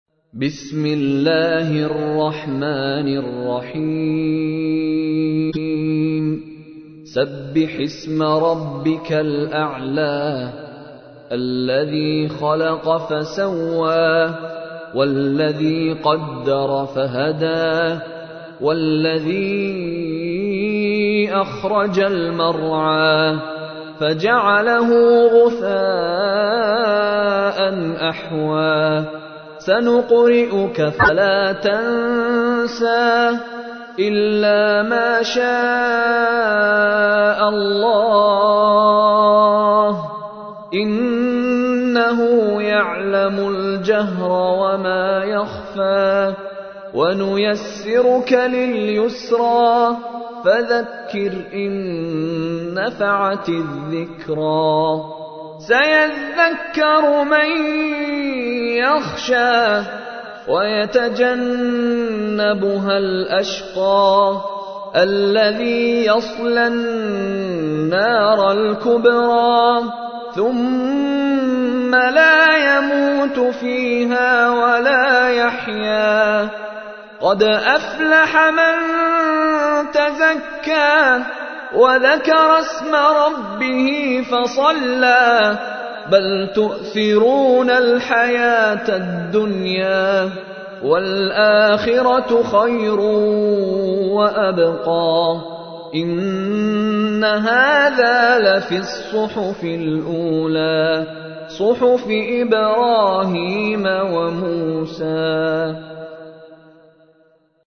تحميل : 87. سورة الأعلى / القارئ مشاري راشد العفاسي / القرآن الكريم / موقع يا حسين